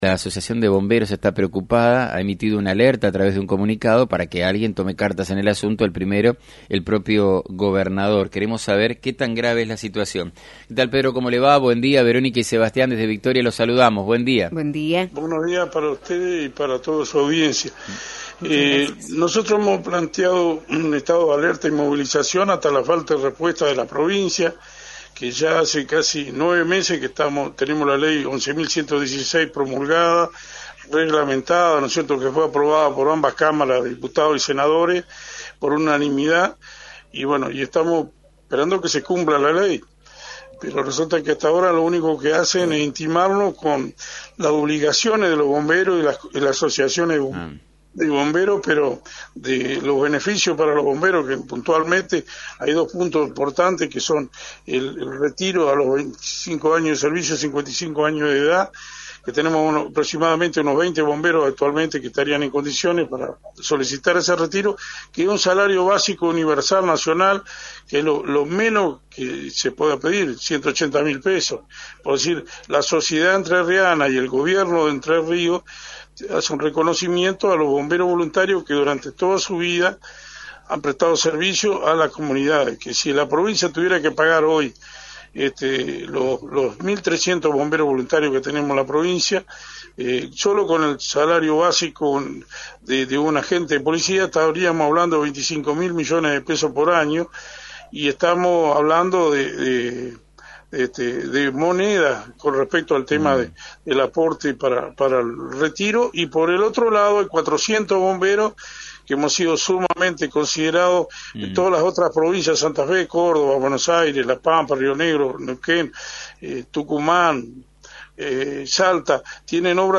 en “Burro de arranque” por FM90.3